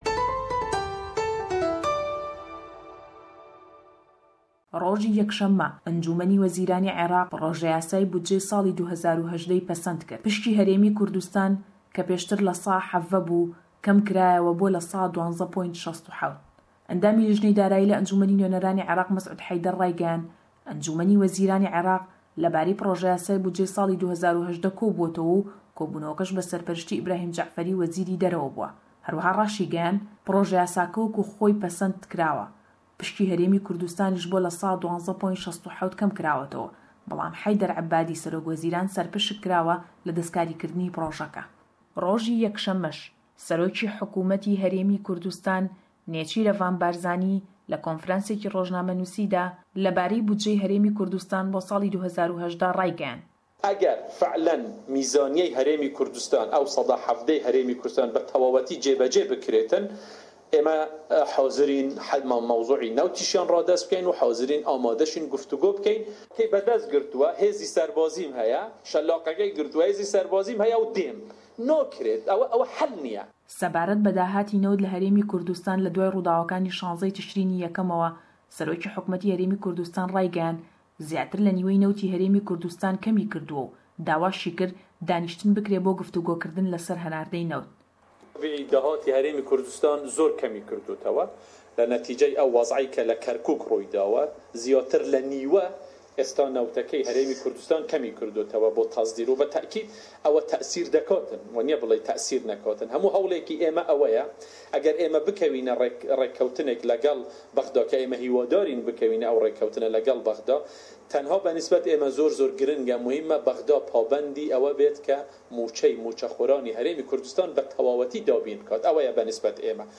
Emane û hewallî kêşe nawxoîyekanî Kurd le em raporte da ye.